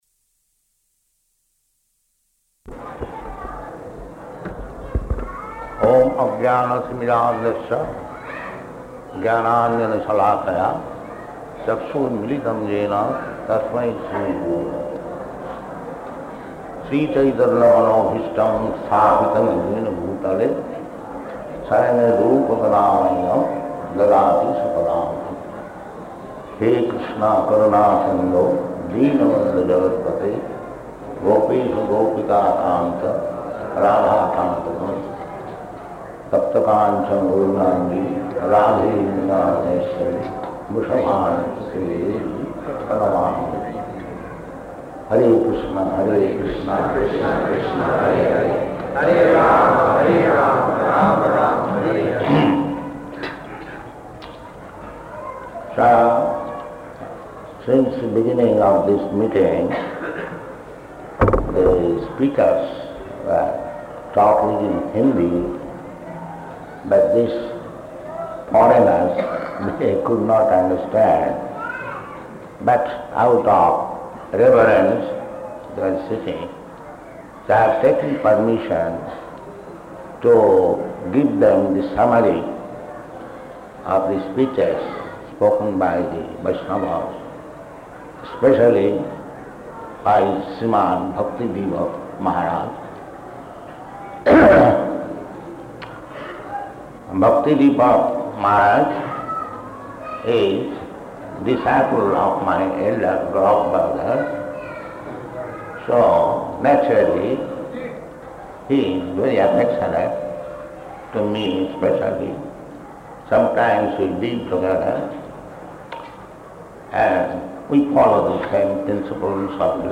Lecture to Devotees